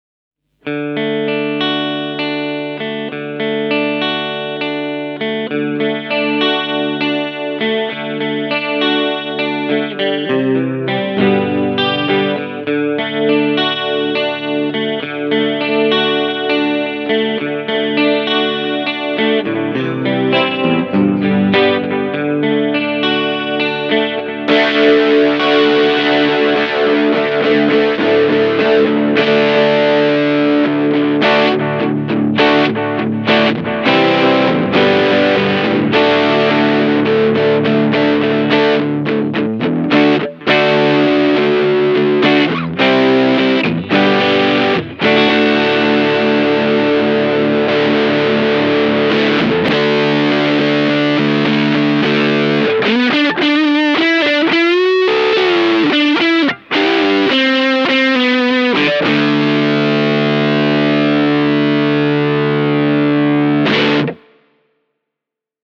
valeton-dapper-mini-e28093-basic-tones.mp3